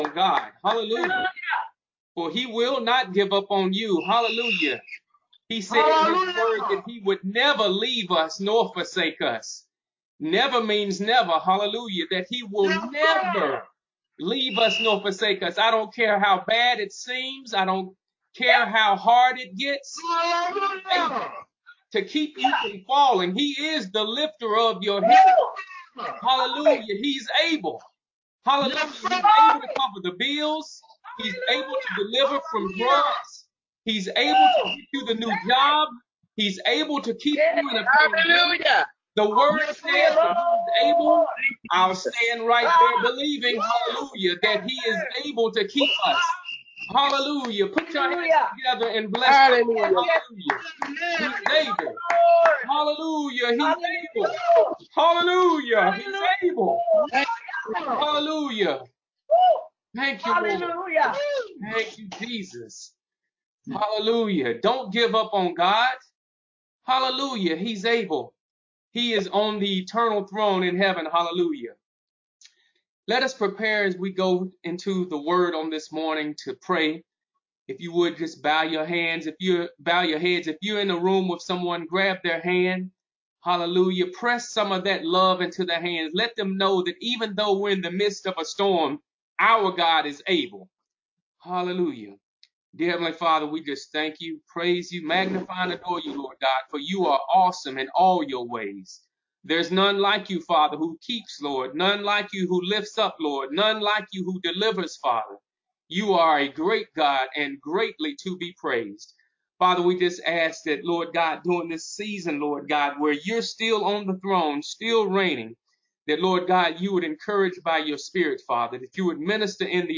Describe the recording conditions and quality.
Recorded as a part of our Virtual Service on Sunday, 19 Jul 2020